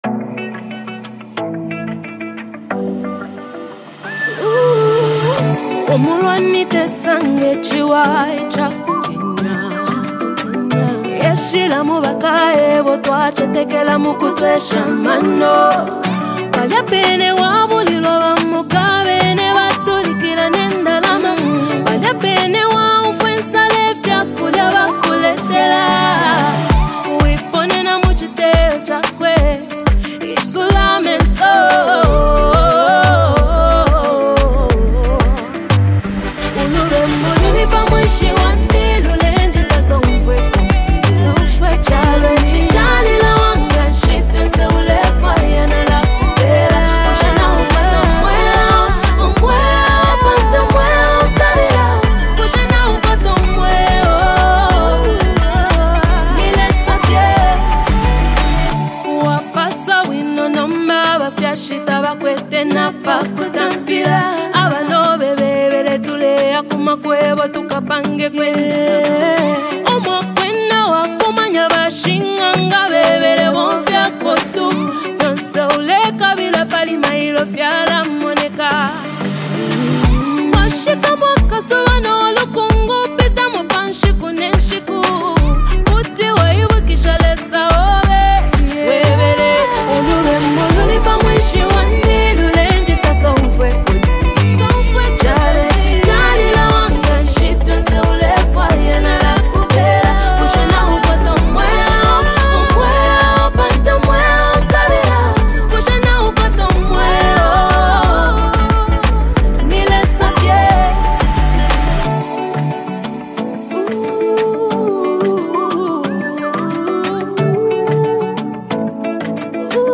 Award-winning Zambian gospel singer